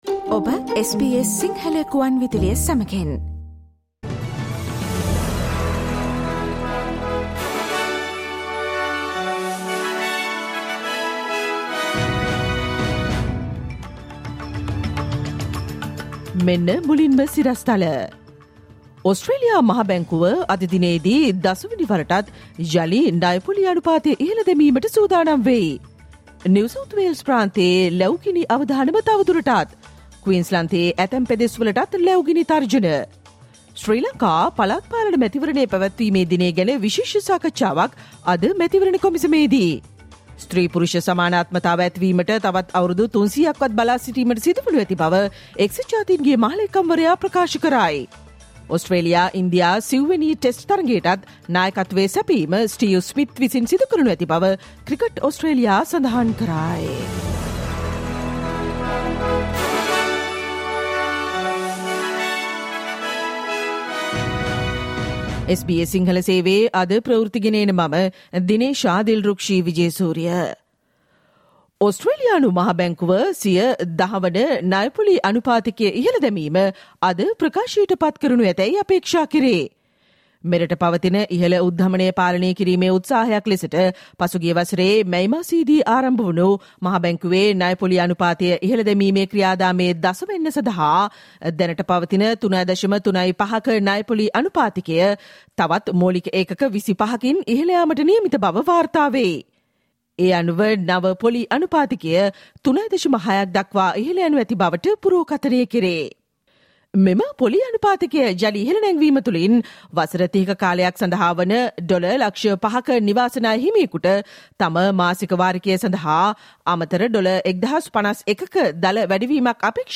Listen to the SBS Sinhala Radio news bulletin on Tuesday 07 March 2023